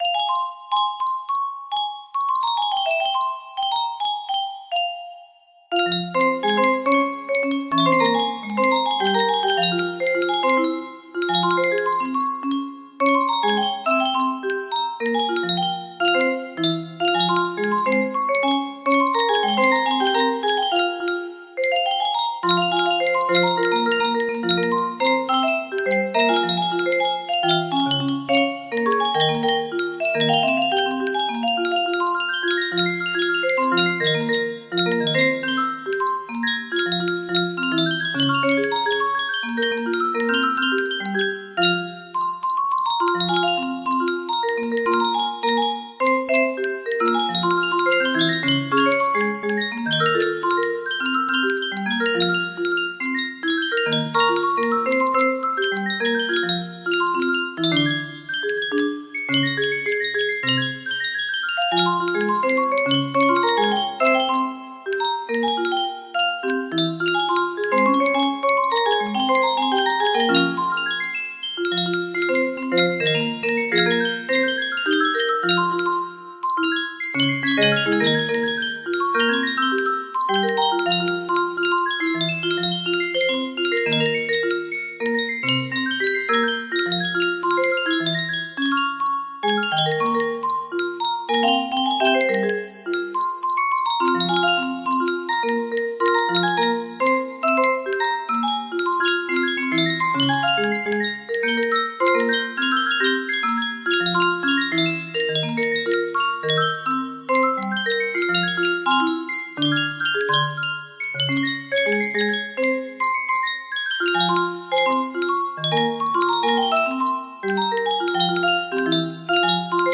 Full range 60
Adjusted the tempo for music box, no reds, Enjoy!